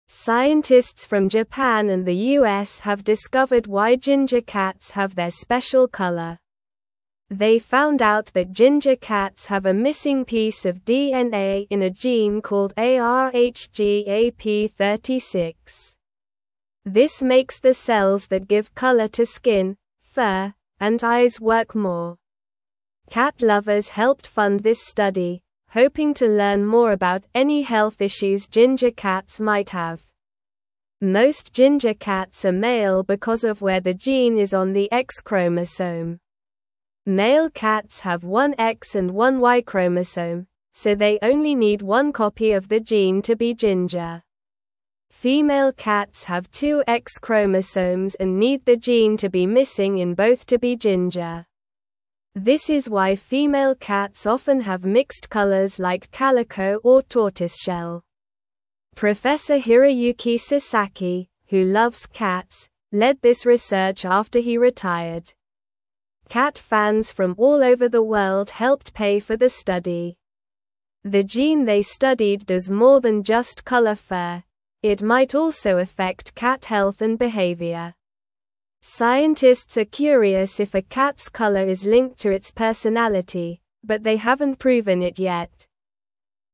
Slow
English-Newsroom-Lower-Intermediate-SLOW-Reading-Why-Ginger-Cats-Are-Mostly-Boys-and-Orange.mp3